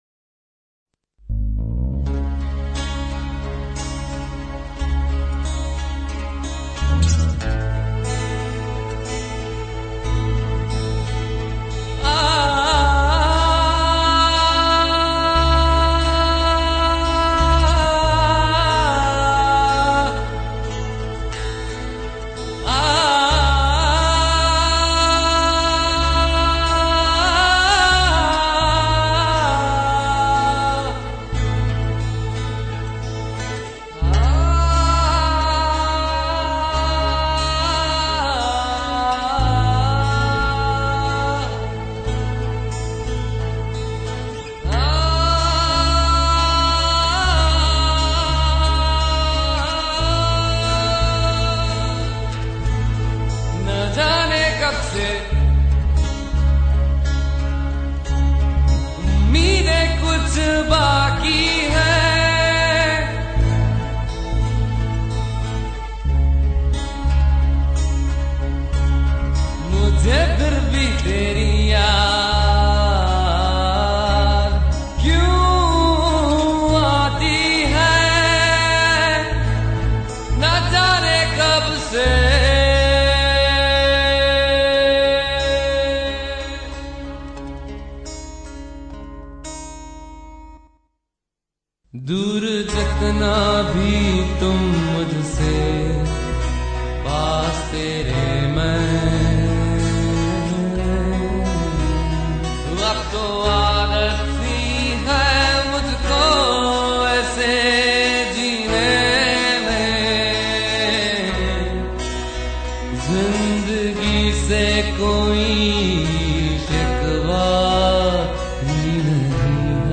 INDIPOP MP3 Songs